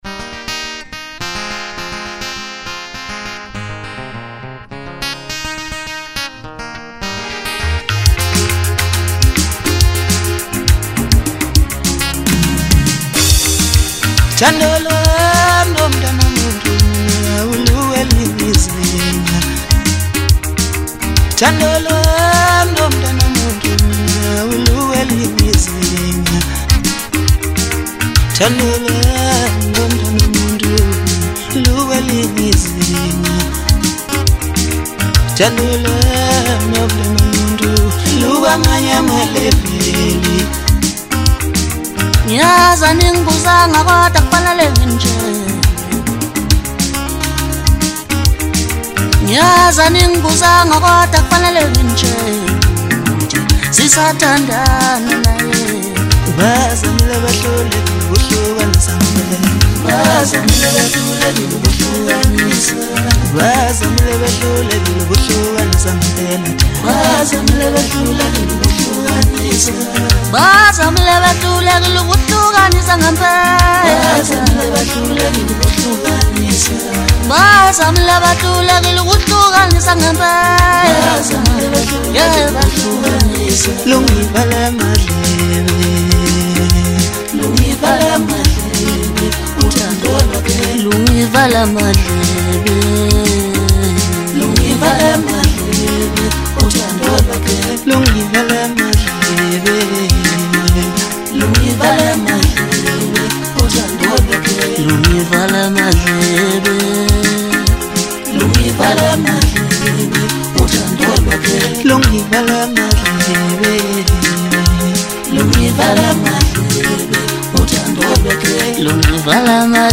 boundary-pushing Maskandi elements
raw energy and uncompromising sound
the duo